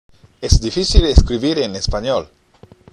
（エス　ディフィシル　エスクリビール　エンネスパニョール）